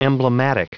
Prononciation du mot emblematic en anglais (fichier audio)
Prononciation du mot : emblematic